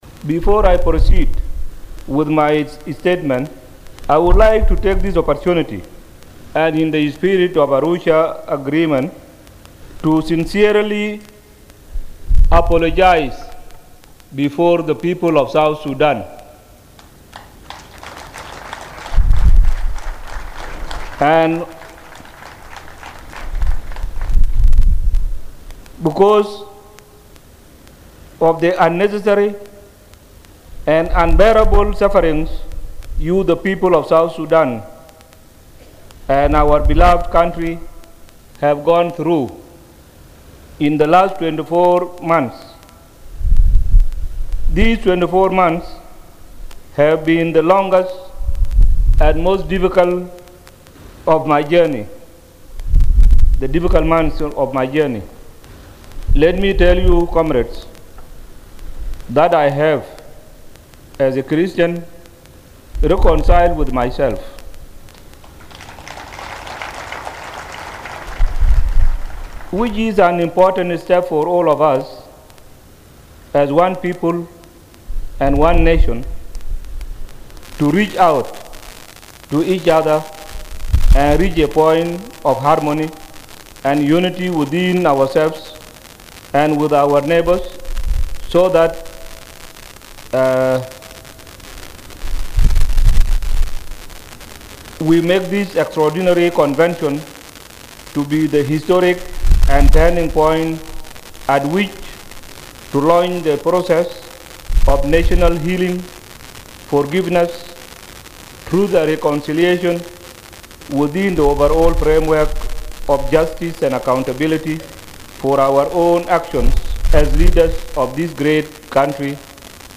President Salva Kiir opened the 1st extraordinary SPLM party convention in Juba on Friday.
In his opening remarks, President Kiir started off with an apology to the people of South Sudan for the conflict.